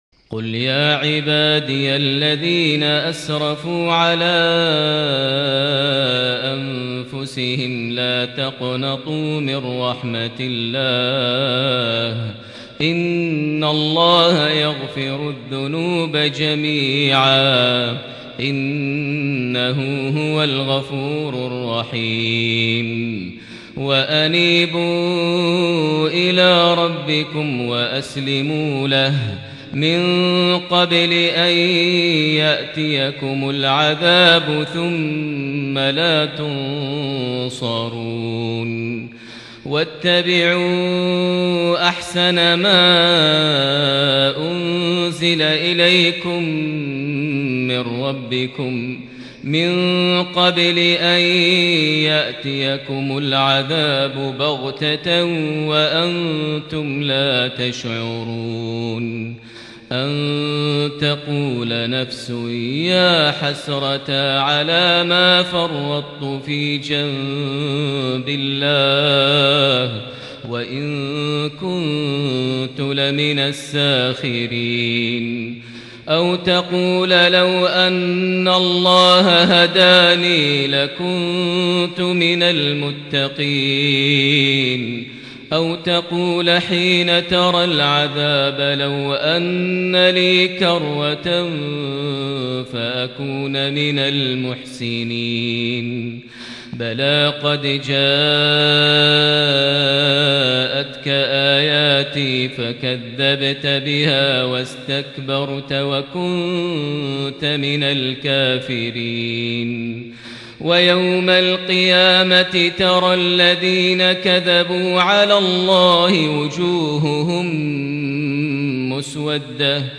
صلاة الفجر ١٣ رجب ١٤٤٠هـ سورة الزمر ٥٣-٧٥ > 1440 هـ > الفروض - تلاوات ماهر المعيقلي